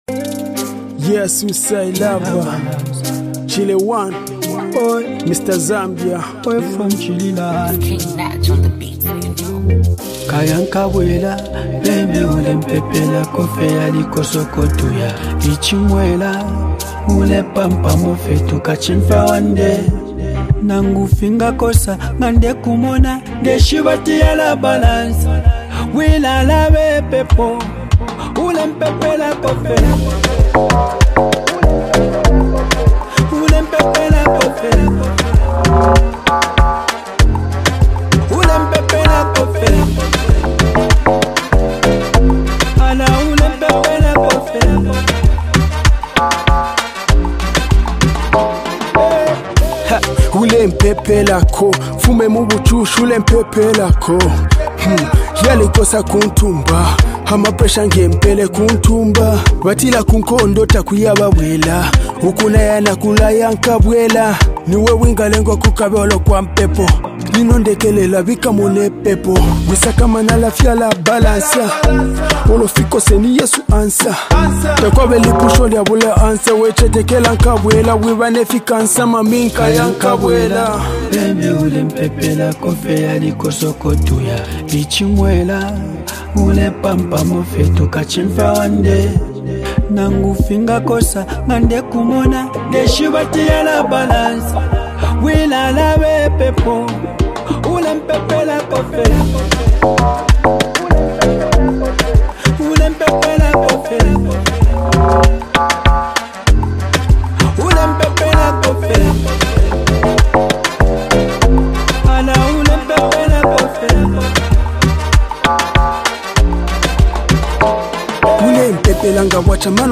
an emotionally charged song